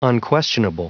Prononciation du mot unquestionable en anglais (fichier audio)
Prononciation du mot : unquestionable